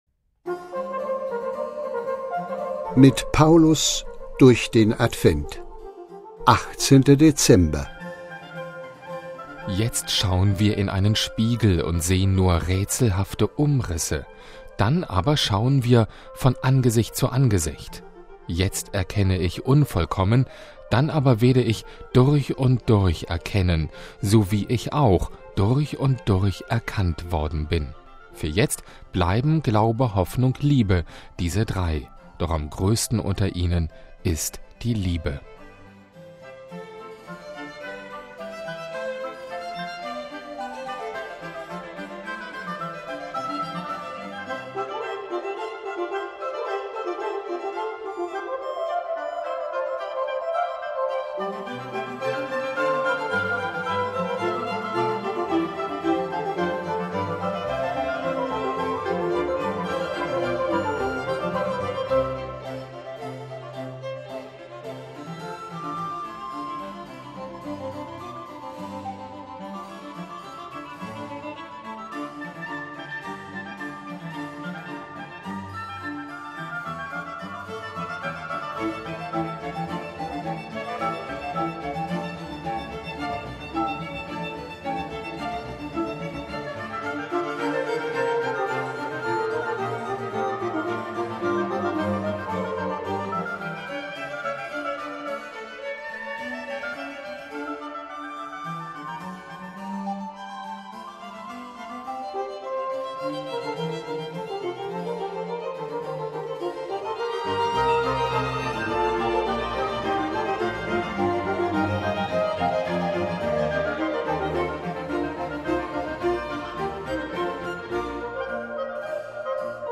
„Mit Paulus durch den Advent“ ist das Motto dieses Audio-Adventskalenders, und an 24 Tagen lesen die Mitarbeiterinnen und Mitarbeiter einen ausgewählten Satz aus den Paulusbriefen.